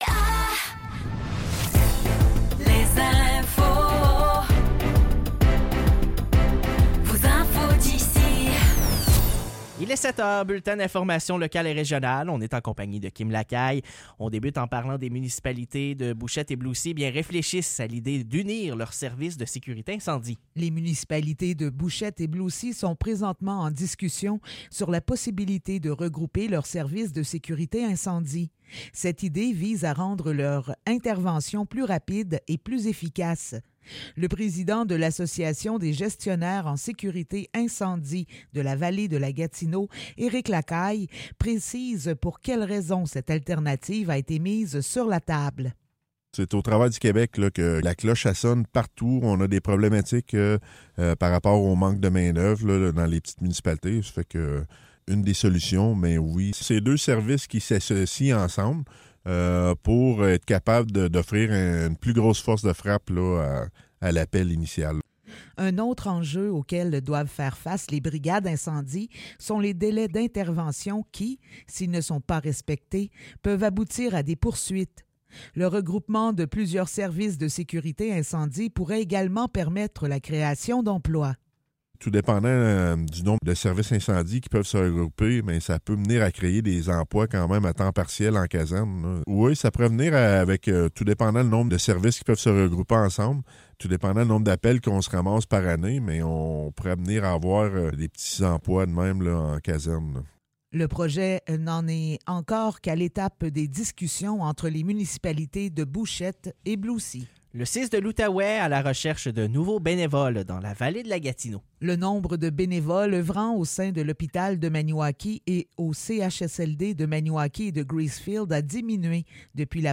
Nouvelles locales - 22 février 2024 - 7 h